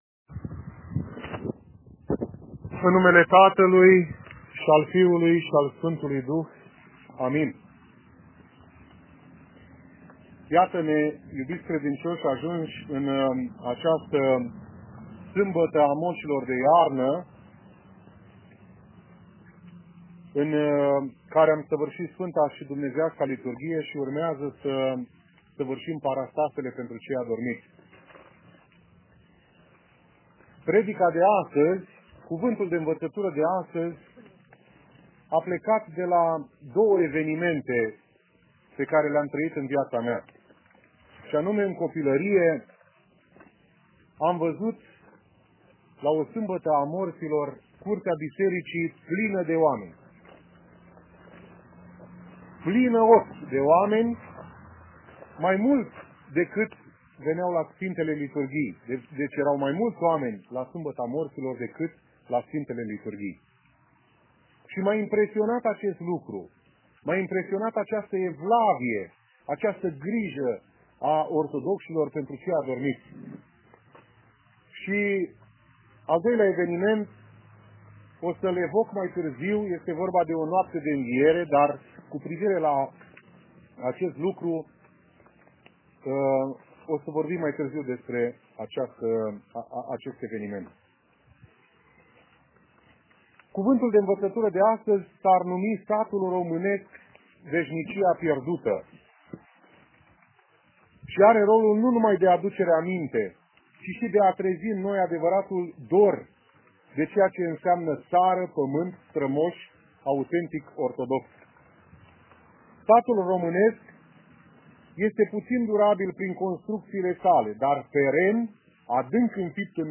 la moșii de iarnă – sâmbăta morților
Predici